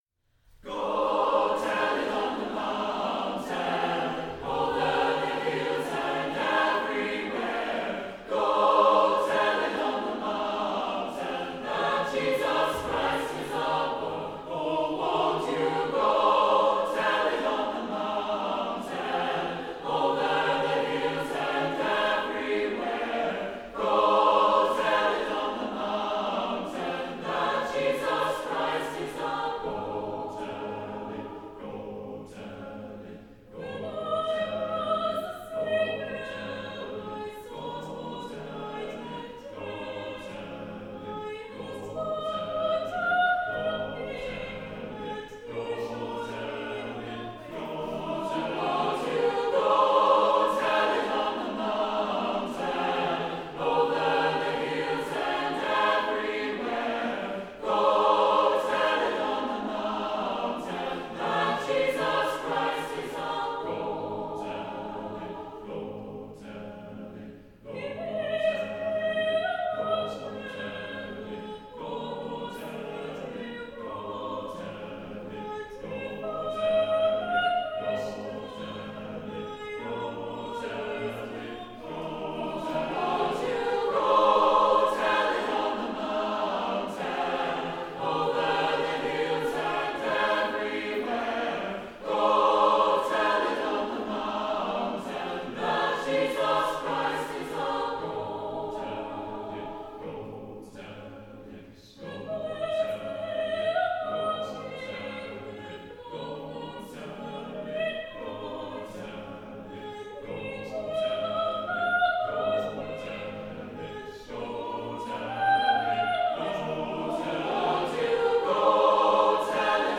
Voicing: SATB divisi a cappella